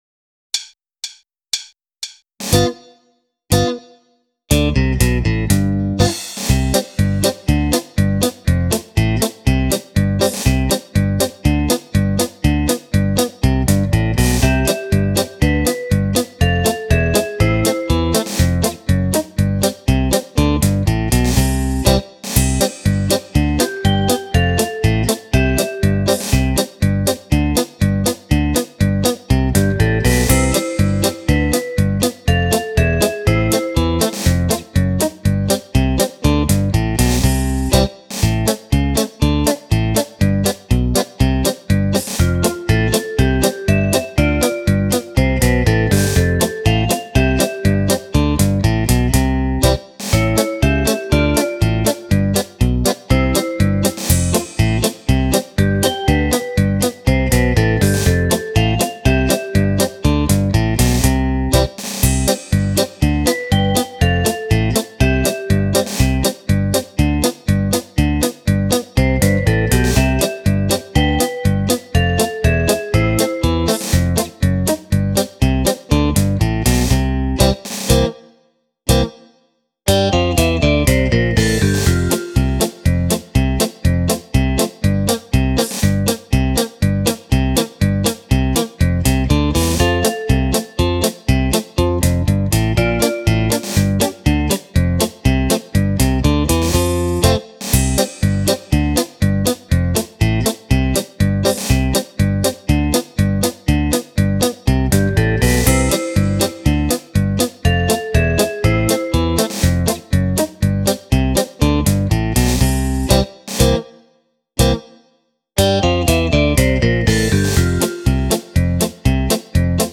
10 ballabili per Fisarmonica
Polka